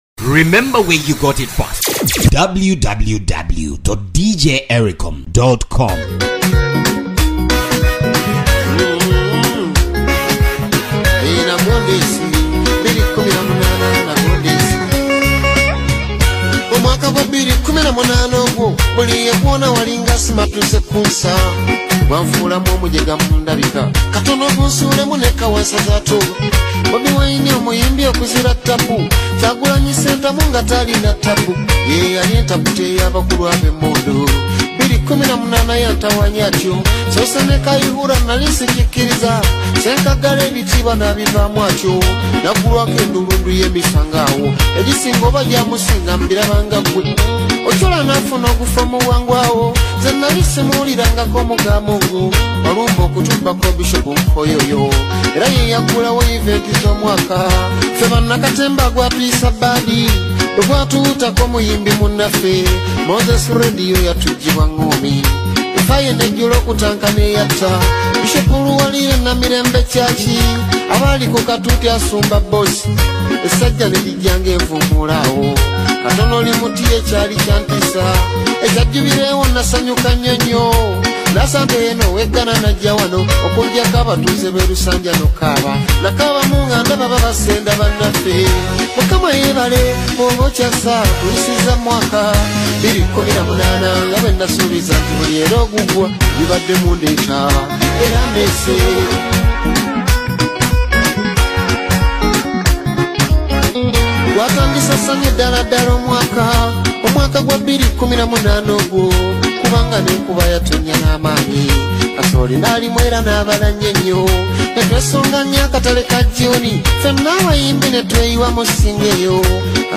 Genre: Kadongo Kamu